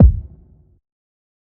TC Kick 12.wav